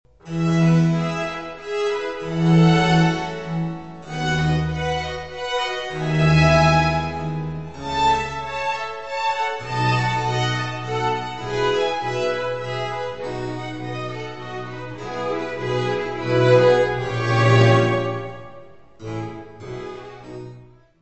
Cantatas
Área:  Música Clássica